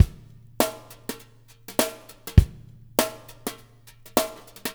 BSH DRUMS -L.wav